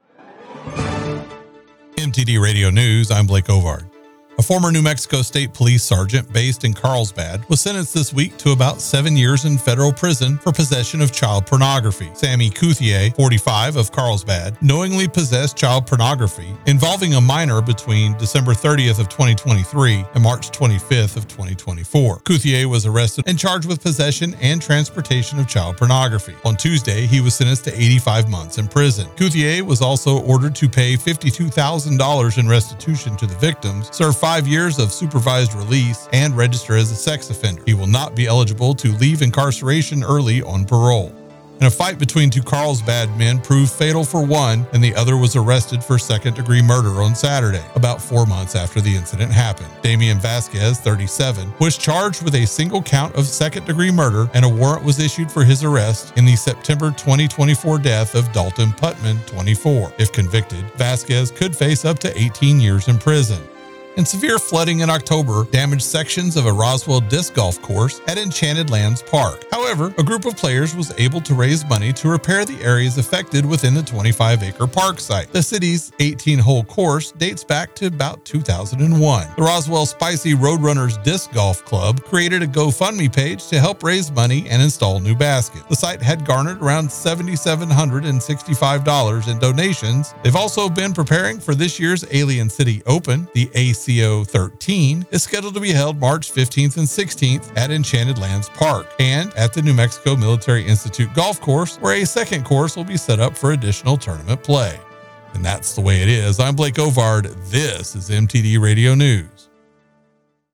W105 News – New Mexico and West Texas